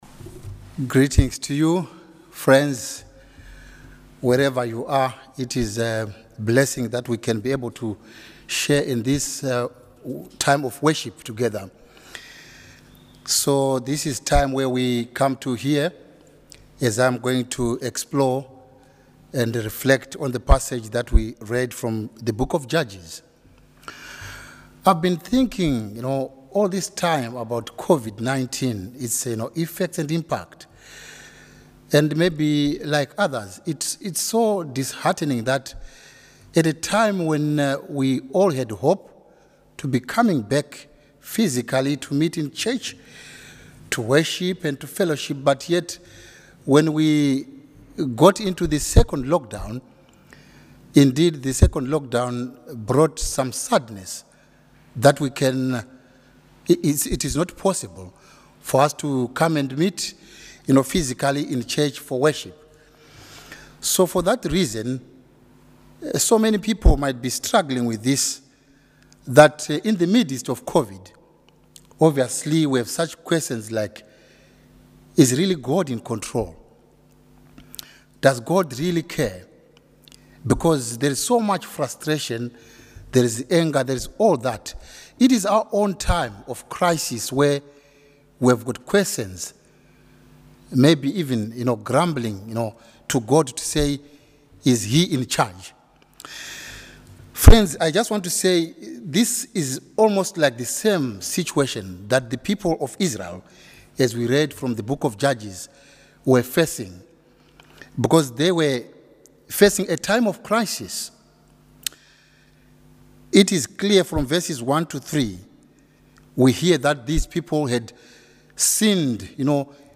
Online Worship 1 Thessalonians , Judges